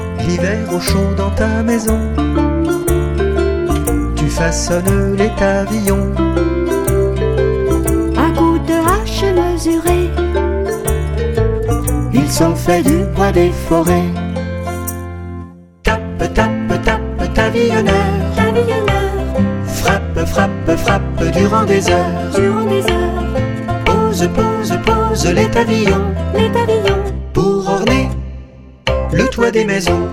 Musicien. Ens. voc. & instr.